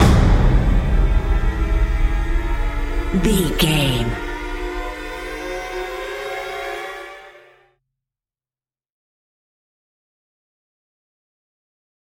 Atonal
tension
ominous
dramatic
eerie
drums
percussion
strings